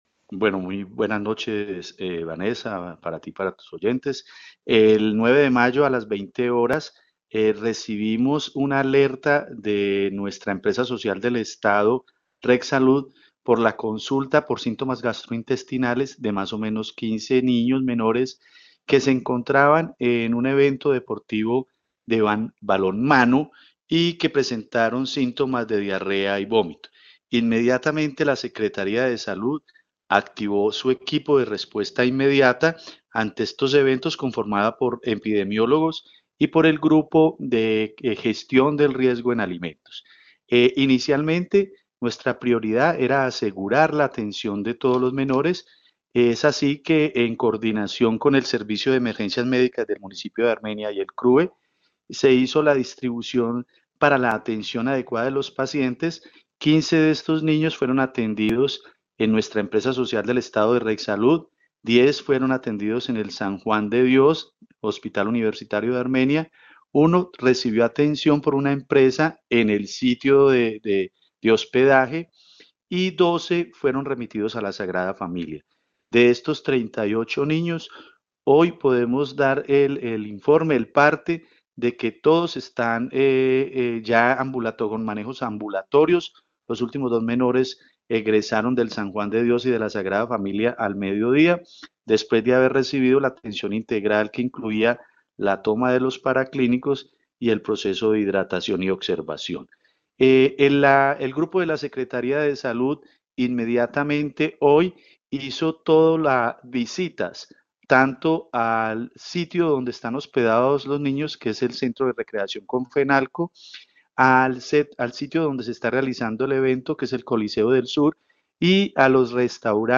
Secretario de Salud de Armenia, César Rincón